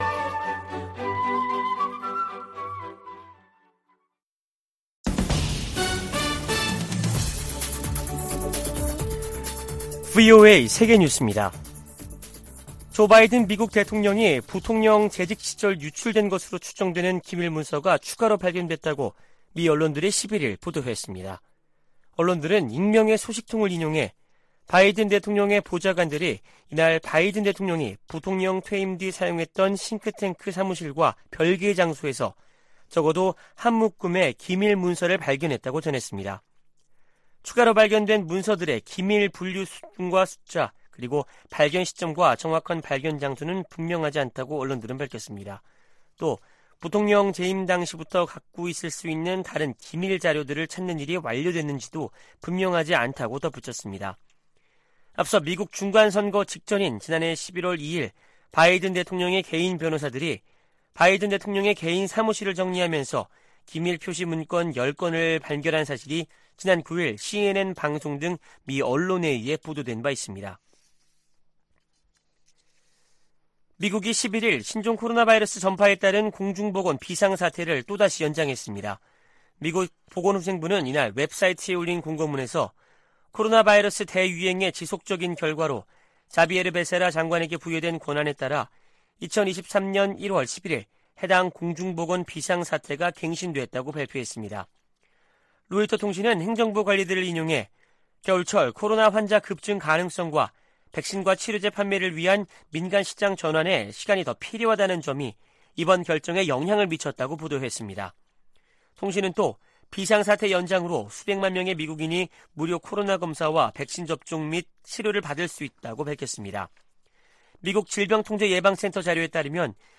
VOA 한국어 아침 뉴스 프로그램 '워싱턴 뉴스 광장' 2023년 1월 13일 방송입니다. 윤석열 한국 대통령이 핵무기 개발 등 북한의 도발과 안보 위협에 대응한 자체 핵 무장 가능성을 언급했습니다. 미국과 일본의 외교・국방 장관이 워싱턴에서 회담을 갖고 북한의 탄도미사일 도발 등에 대응해 미한일 3자 협력을 강화하기로 거듭 확인했습니다.